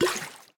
Minecraft Version Minecraft Version 25w18a Latest Release | Latest Snapshot 25w18a / assets / minecraft / sounds / item / bottle / empty1.ogg Compare With Compare With Latest Release | Latest Snapshot